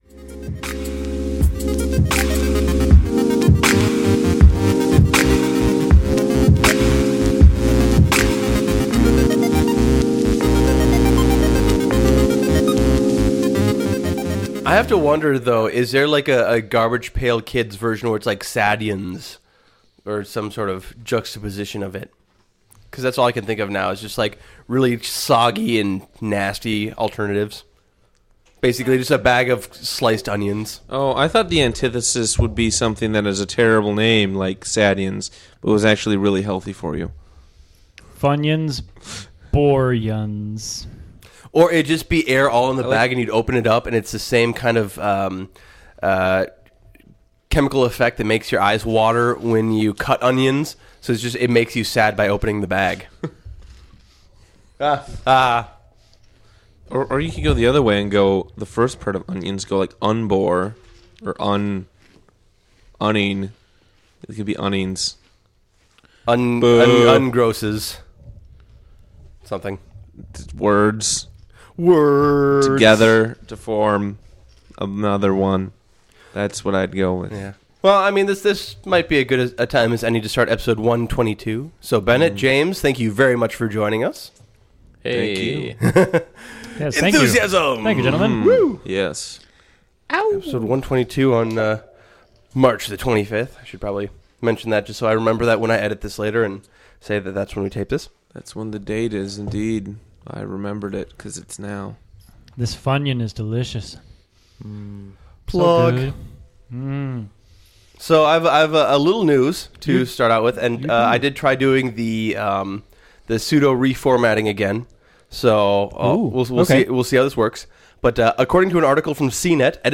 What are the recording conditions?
Taped at The Wheels Brewing Co. Studio, Minneapolis, MN on March 25, 2014.